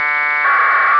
вот короткая запись пакета